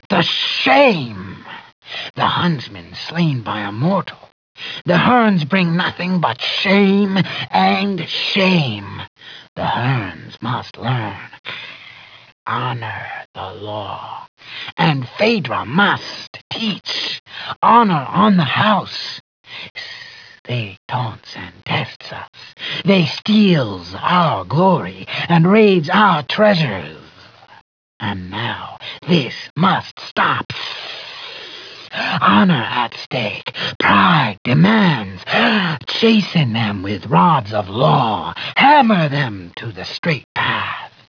BS-audio-Clannfear_Taunts.wav